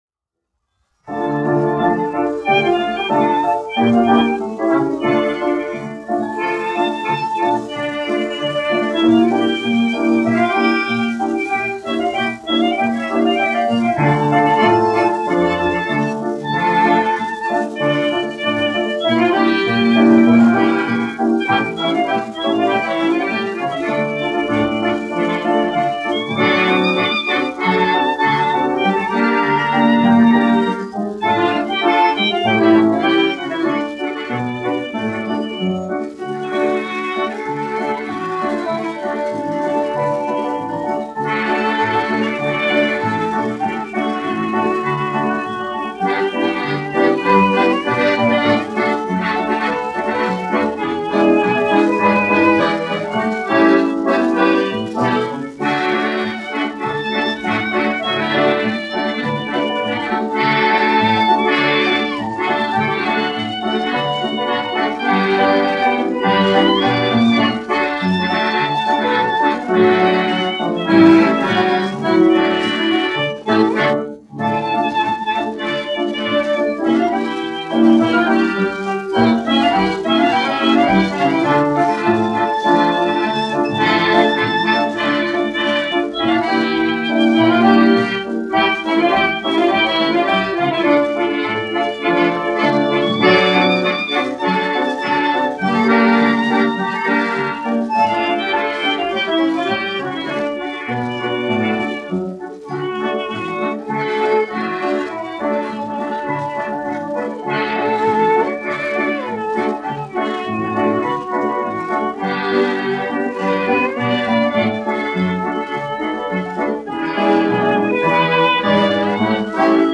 1 skpl. : analogs, 78 apgr/min, mono ; 25 cm
Fokstroti
Populārā instrumentālā mūzika
Skaņuplate